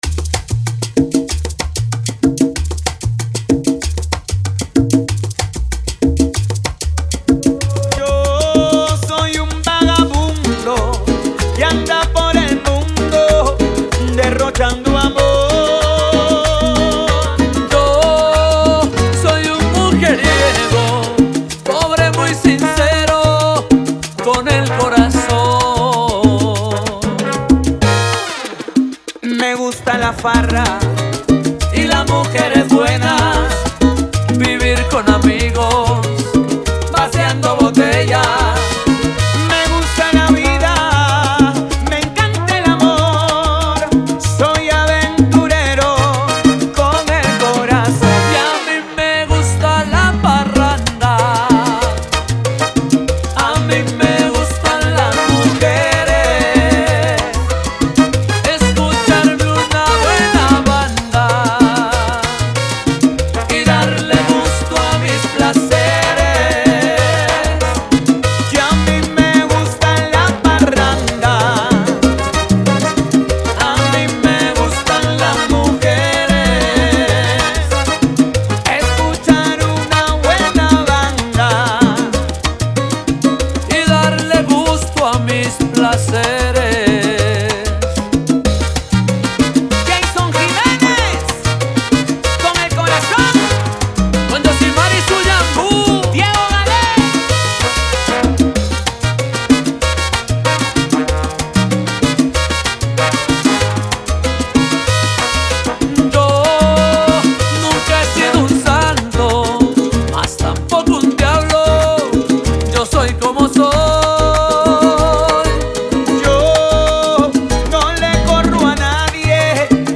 Genre: Salsa.